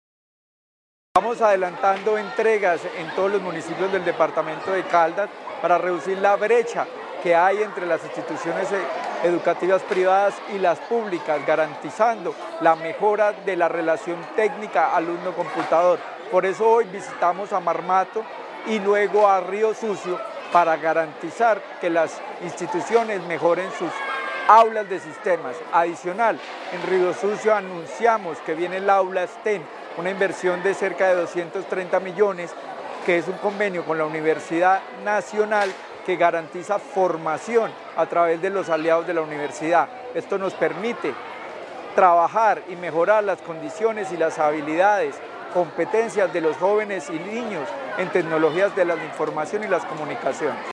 Secretario de Educación de Caldas, Luis Herney Vargas Barrera.